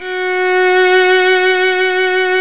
Index of /90_sSampleCDs/Roland - String Master Series/STR_Violin 1-3vb/STR_Vln2 _ marc
STR  VL F#5.wav